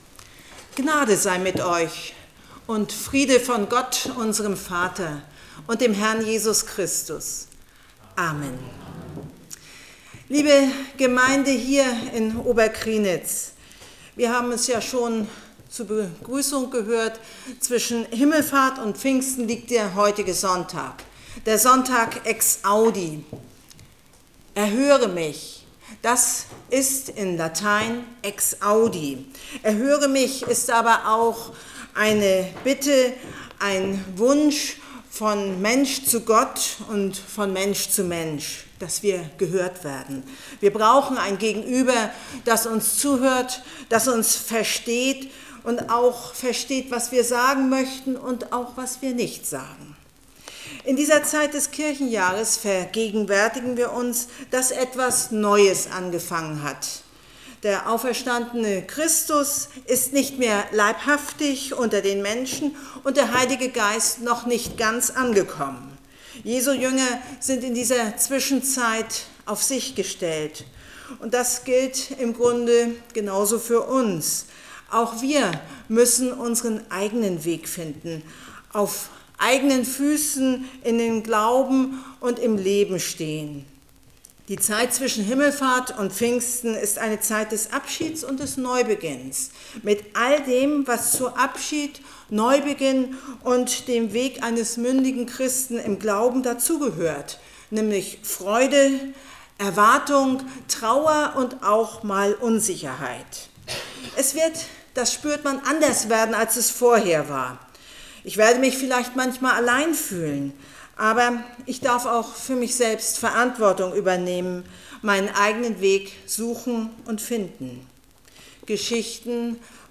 Eine Gastpredigt